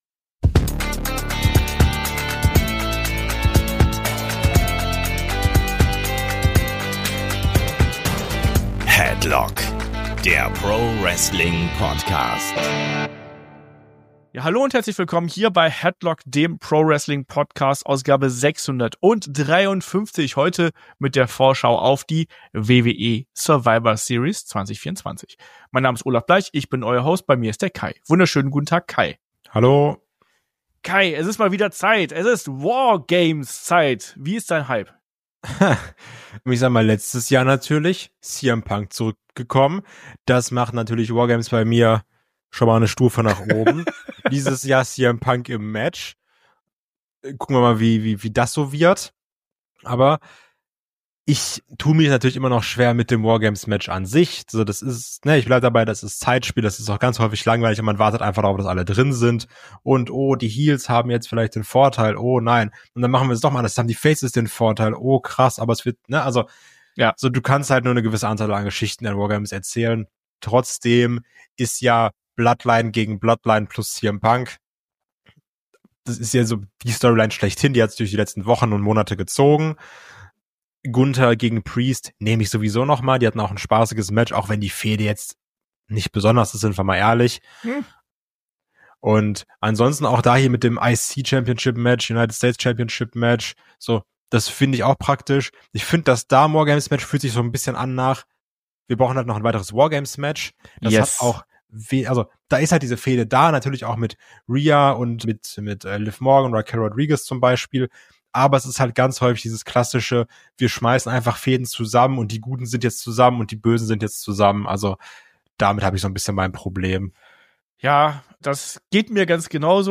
Das erfahrt ihr im Vorschau-Podcast zur WWE Survivor Series 2024.